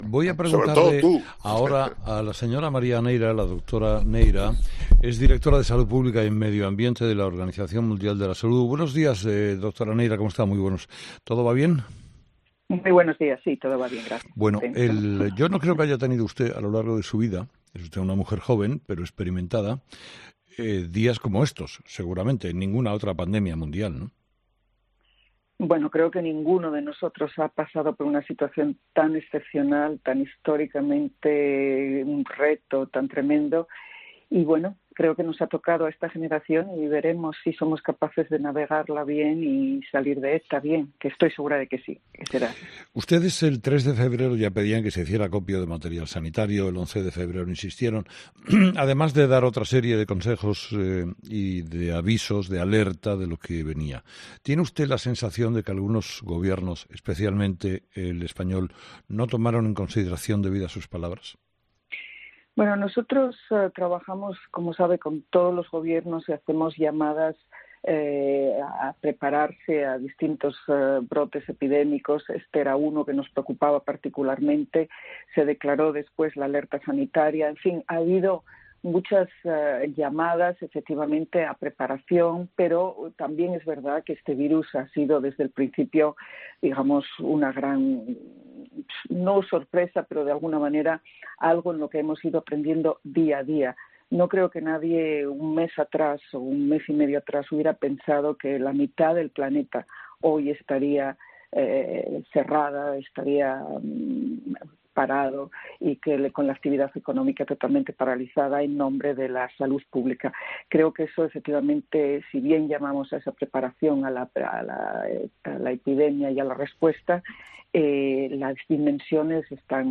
En una entrevista este jueves en "Herrera en COPE", Neira ha asegurado que pidieron a "todos los Gobiernos" que estuvieran atentos a este brote epidémico, aunque también reconoce que ni la propia OMS creía que la mitad del planeta acabaría confinada en su casa.